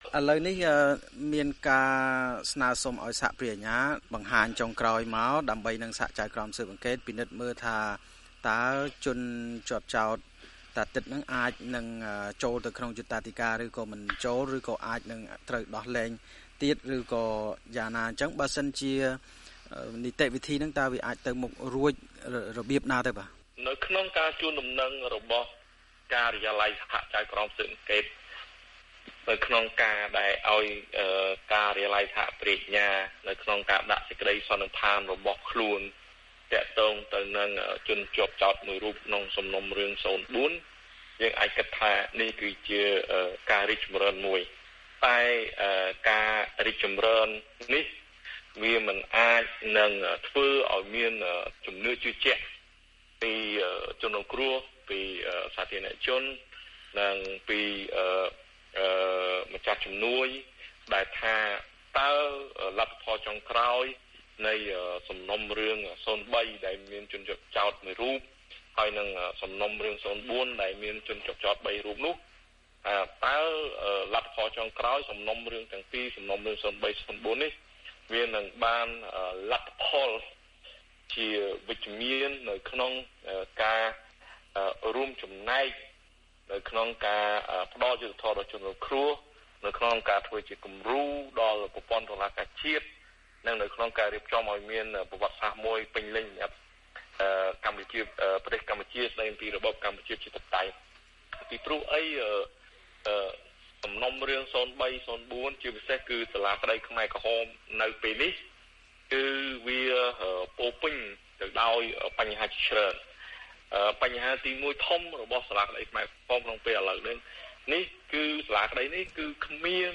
បទសម្ភាសន៍VOA